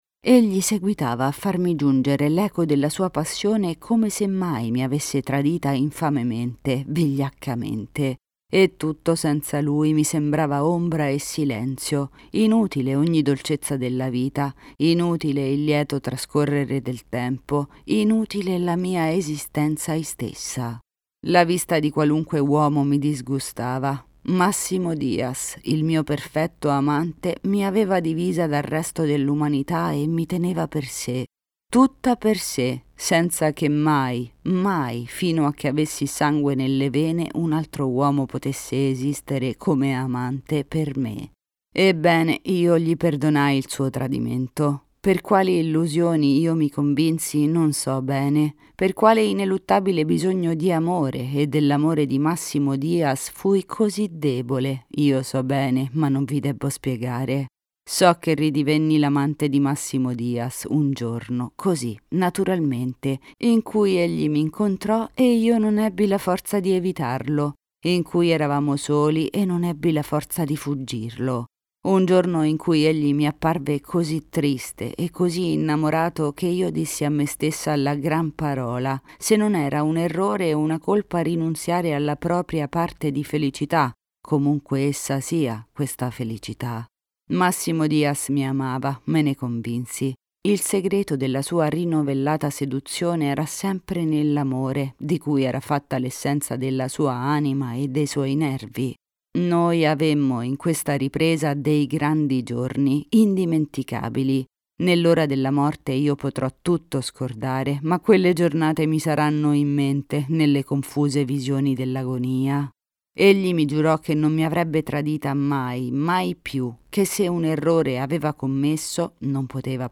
ISBN audiobook